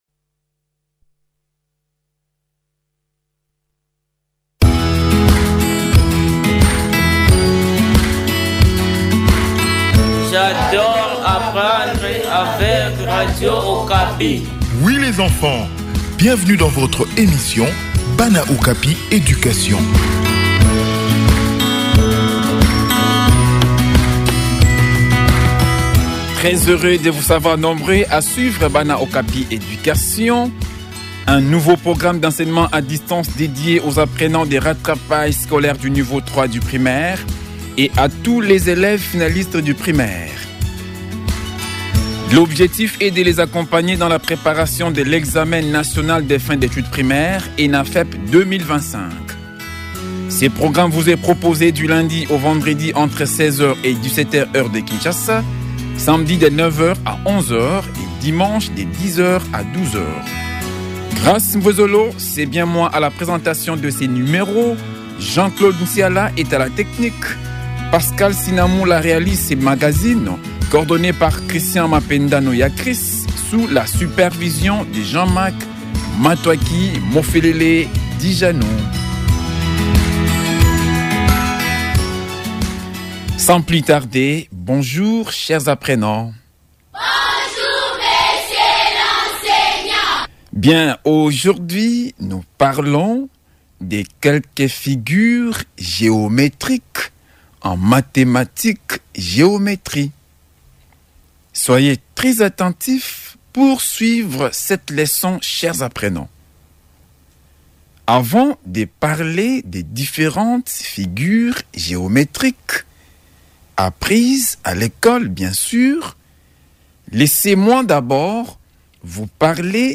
Ce numéro de Bana Okapi Education propose une leçon sur les quatre figures géométriques.